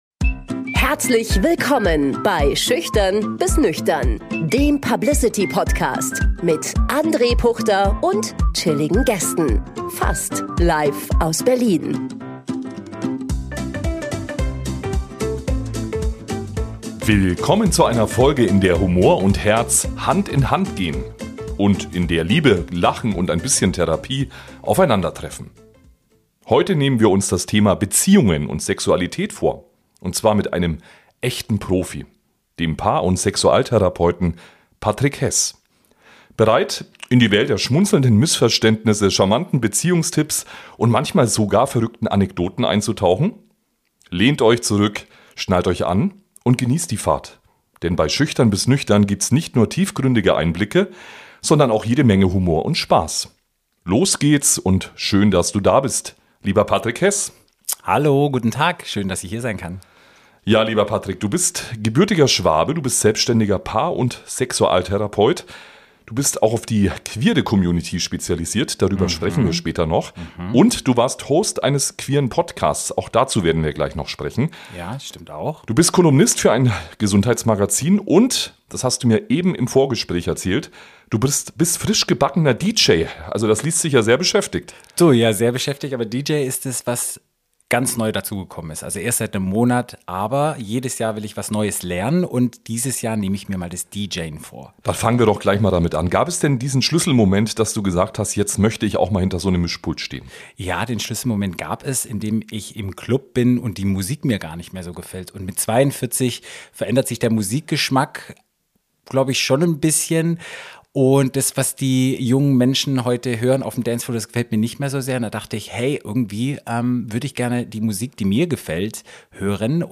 Ein Gespräch über Beziehungen jenseits von Klischees – ehrlich, differenziert und überraschend alltagsnah.